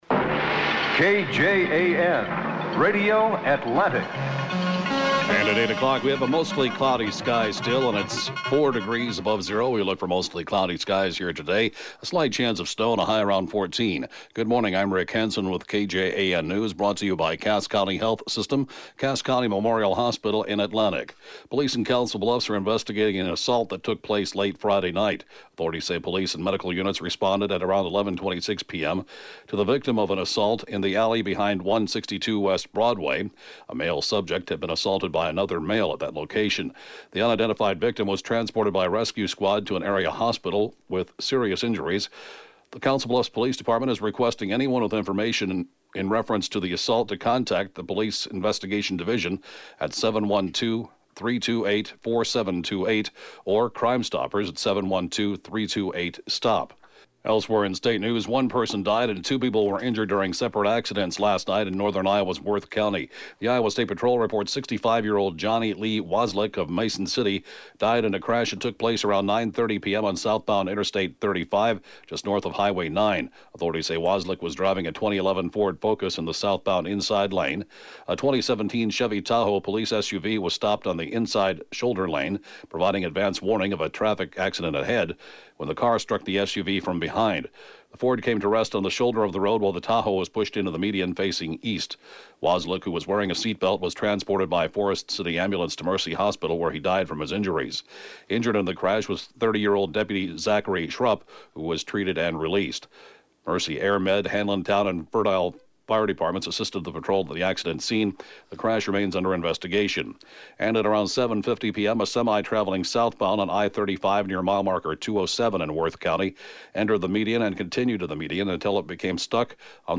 (Podcast) KJAN 8-a.m. News, 2/10/2018